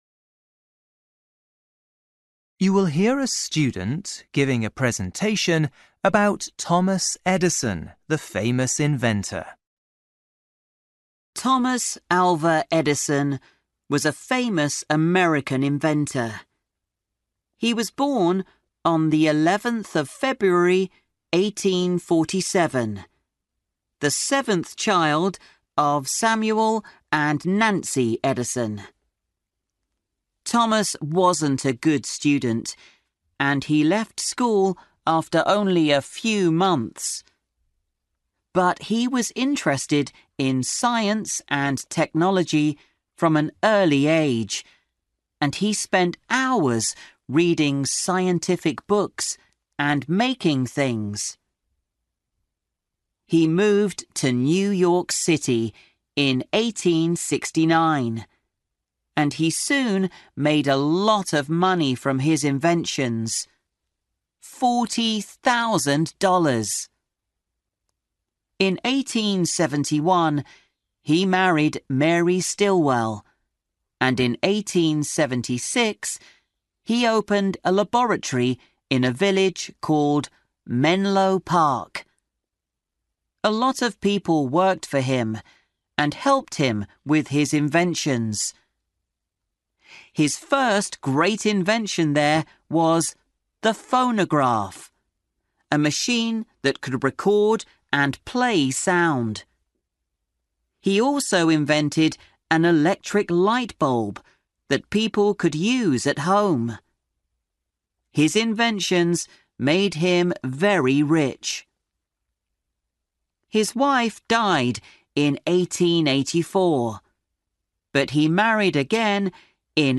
You will hear a student giving a presentation about a famous inventor.